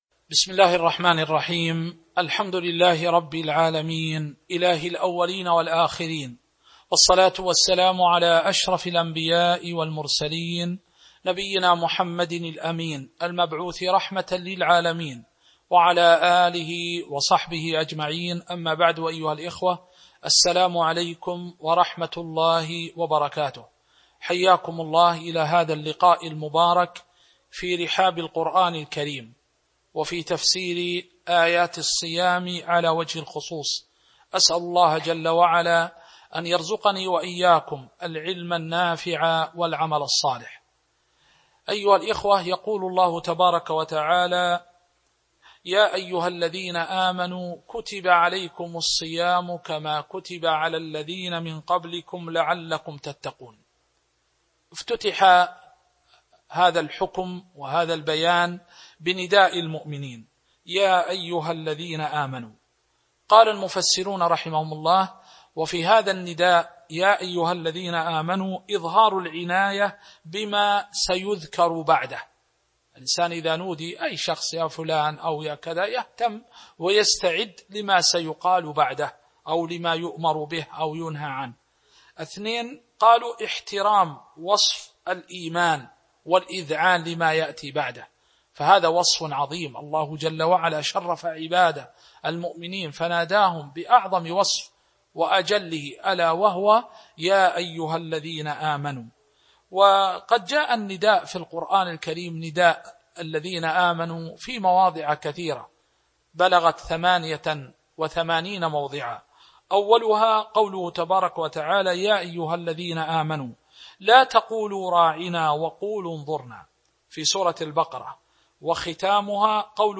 تاريخ النشر ١٢ رمضان ١٤٤٢ هـ المكان: المسجد النبوي الشيخ